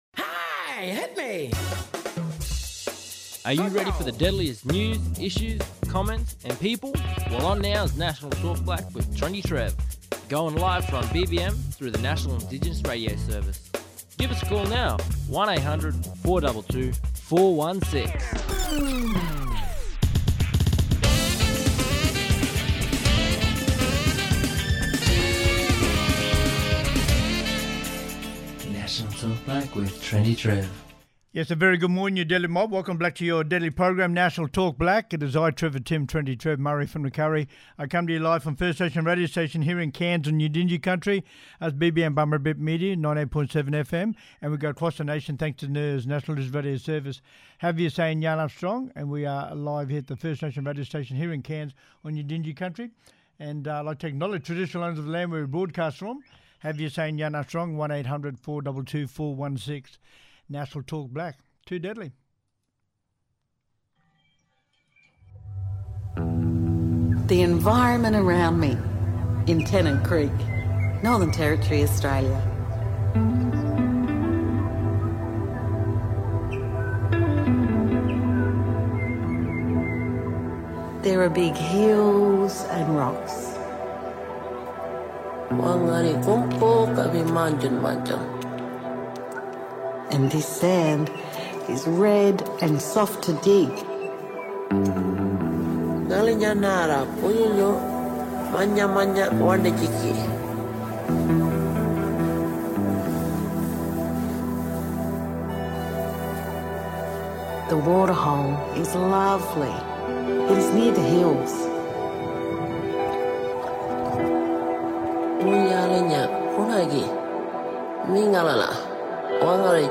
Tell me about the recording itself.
Special Guests Live today from the third and final day of the 2025 Winds of Zenadth Cultural Festival.